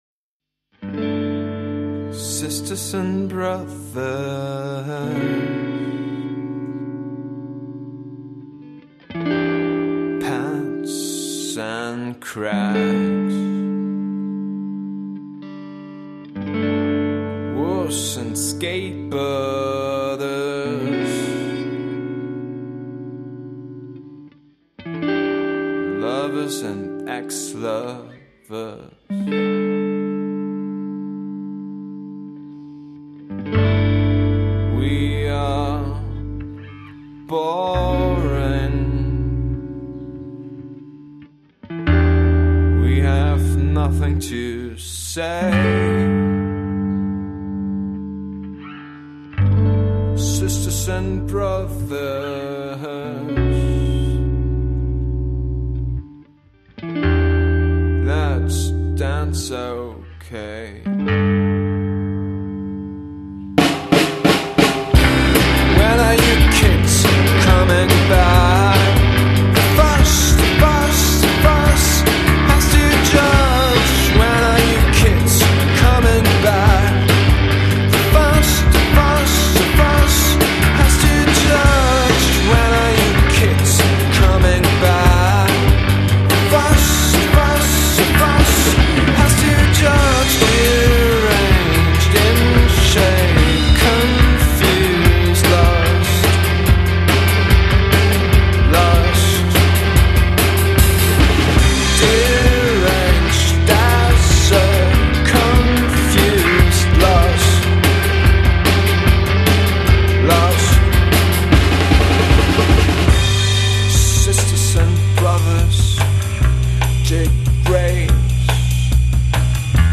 Rockband